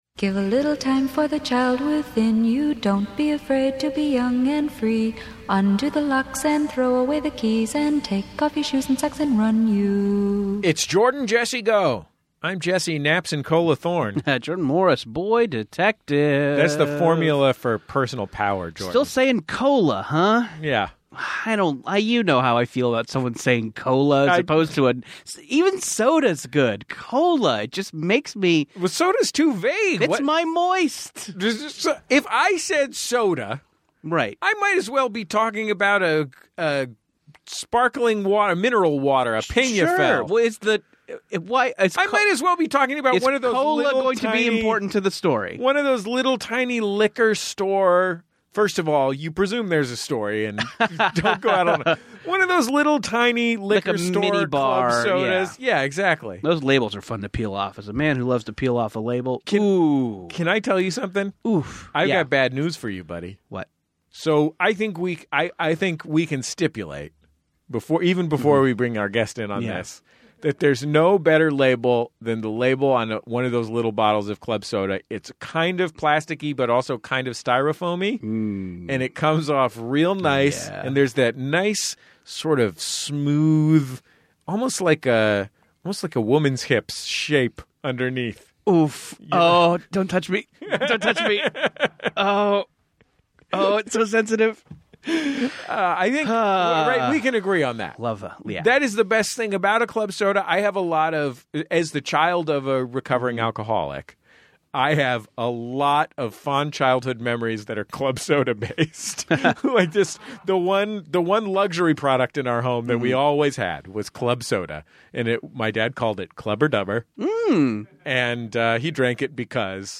Society & Culture, Comedy, Tv & Film